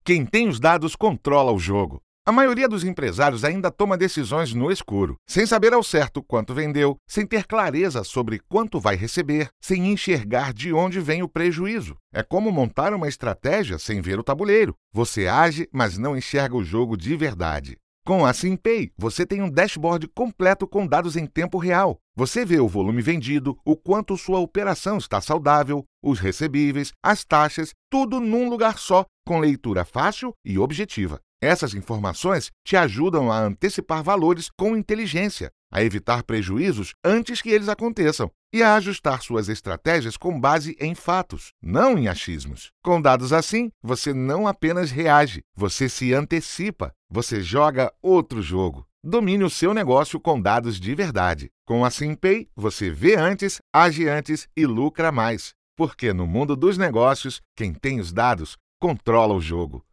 Coca cola Voice Over Commercial Actor + Voice Over Jobs
My range varies from warm and friendly to authoritative.
I am a baritone....